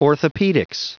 Prononciation du mot orthopaedics en anglais (fichier audio)
Prononciation du mot : orthopaedics
orthopaedics.wav